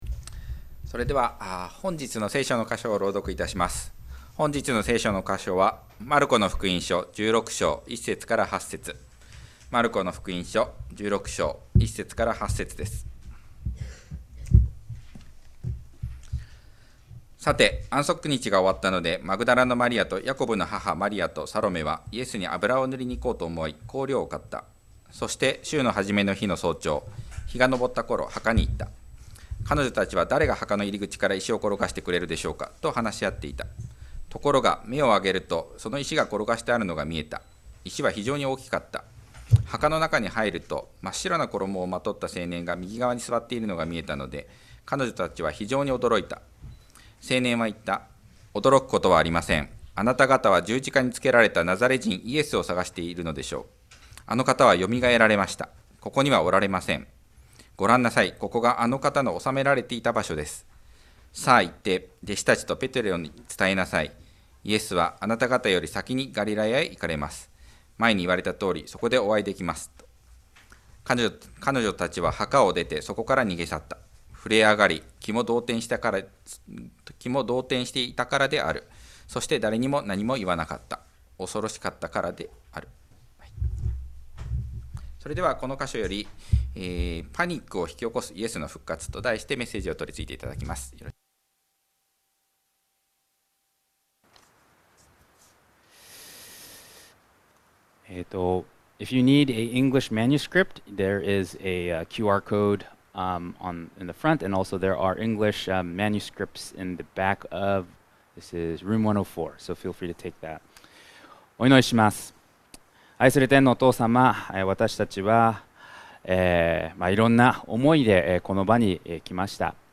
礼拝式順